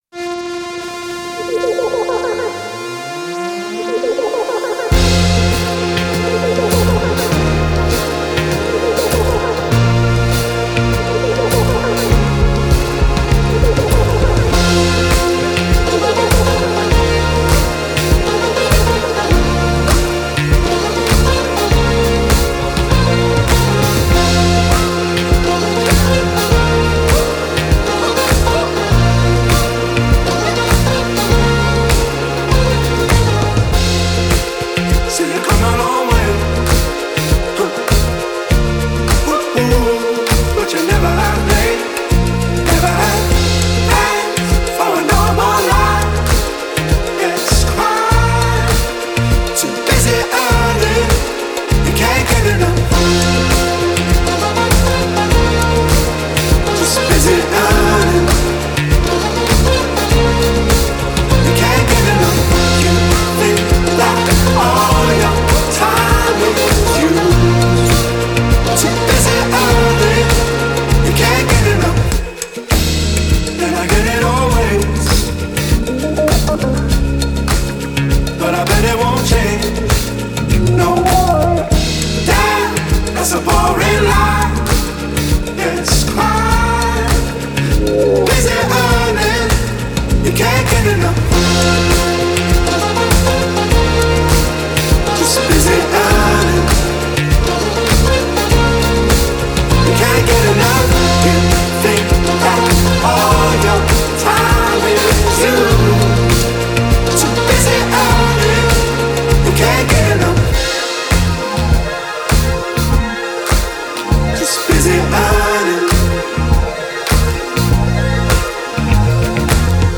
Leur musique électronique est basée sur le funk.